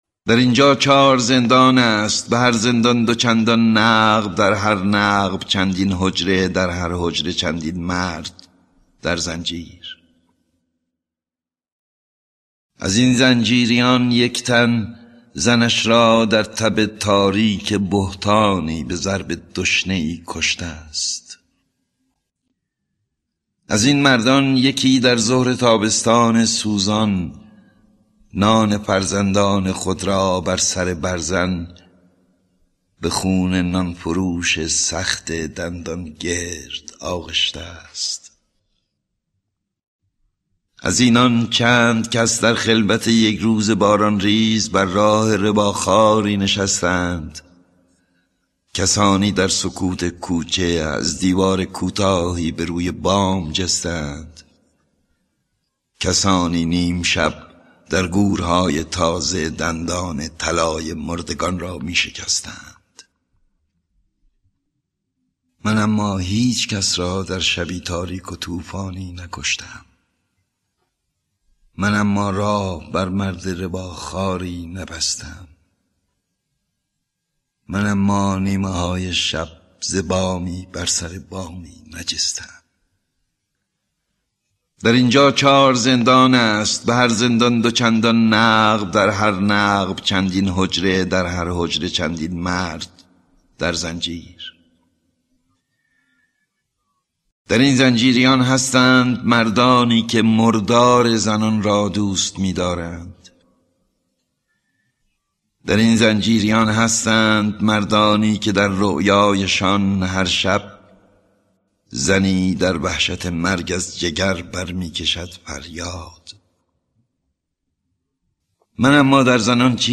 دکلمه شعر کیفر با صدای احمد شاملو